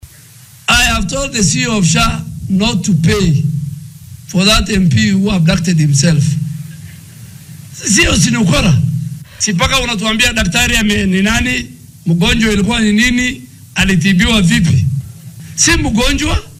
Wasiirka wasaaradda caafimaadka dalka Aadan Barre Ducaale oo ka hadlay magaalada Eldoret ayaa ka dhawaajiyay in maamulka caymiska caafimaadka bulshada ee SHA